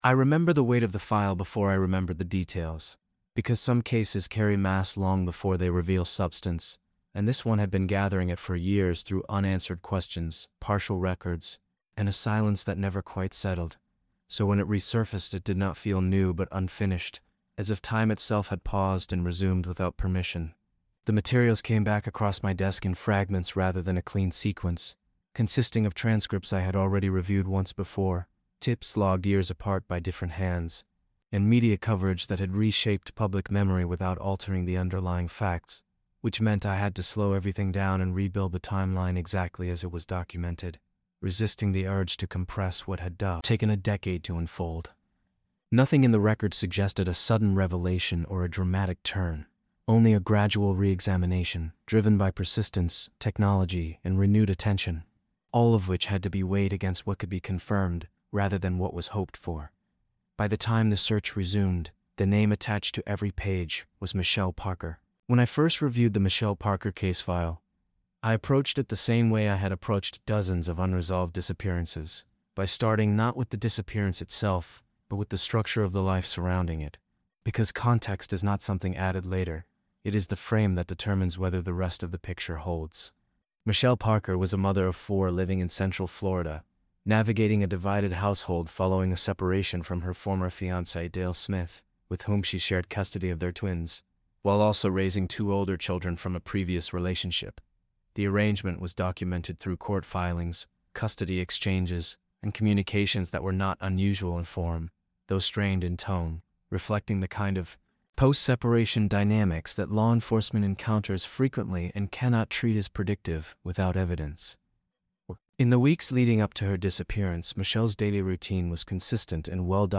Told through a first-person detective narrator and designed specifically for Text-to-Speech delivery, the story reconstructs the case using only verified records, official statements, and documented investigative actions.